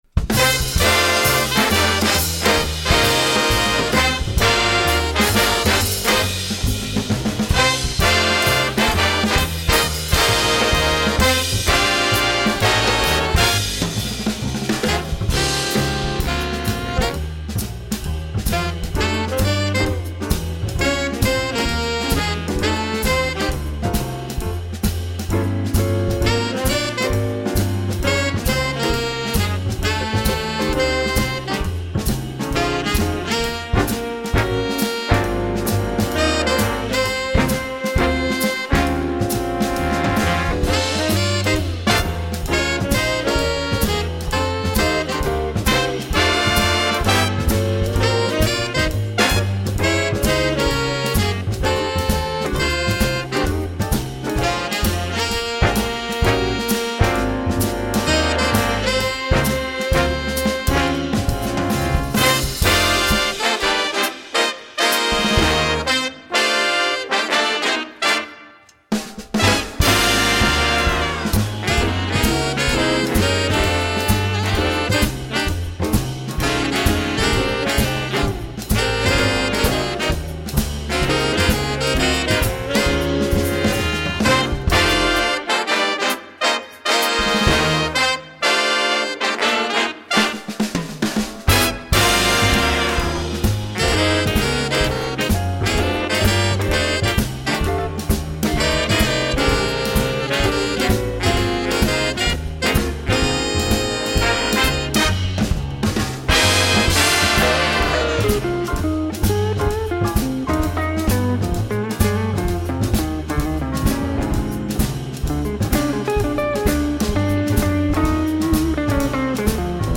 Voicing: Jazz Band